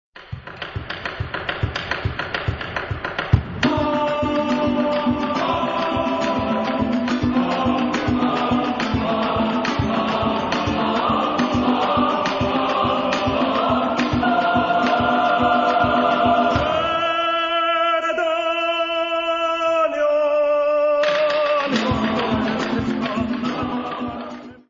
Genre-Style-Form: Secular ; Latin american ; Folk music
Mood of the piece: joyous ; rhythmic
Type of Choir: SATB  (4 mixed voices )
Soloist(s): Tenor (1)  (1 soloist(s))
Instrumentation: Cuatro  (1 instrumental part(s))
Tonality: A major